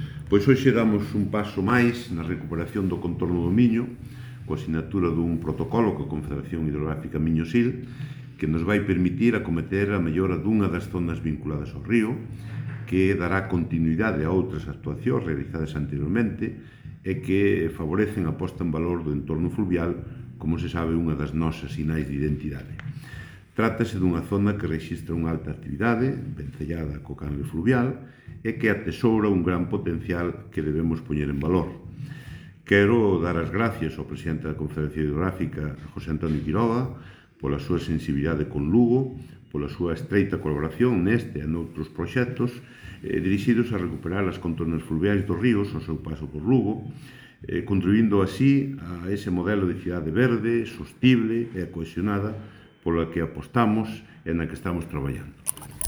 • O alcalde de Lugo, Miguel Fernández, sobre a sinatura do protocolo do CHMS |